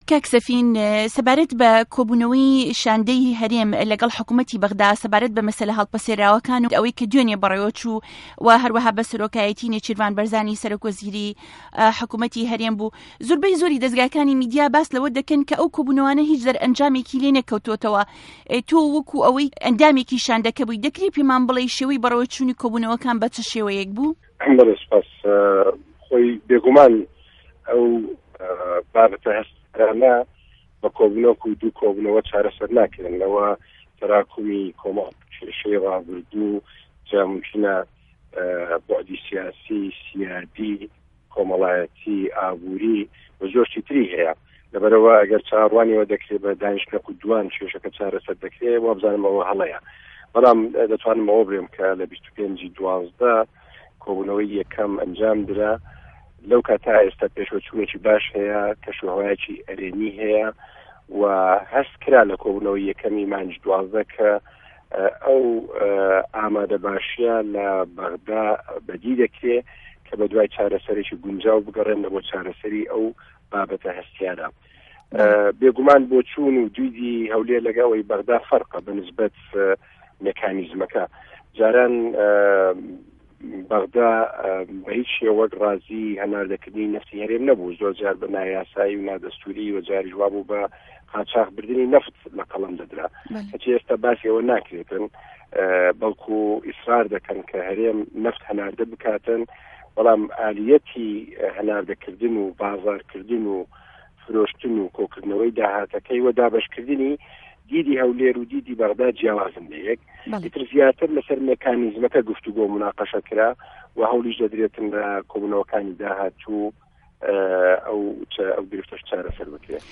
گفتوگۆ ڵه‌گه‌ڵ سه‌فین دزه‌یی 18ی 2ی ساڵی 2014